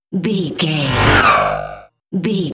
Sci fi airy whoosh
Sound Effects
futuristic
whoosh